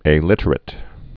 (ā-lĭtər-ĭt)